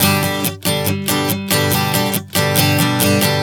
Strum 140 G 04.wav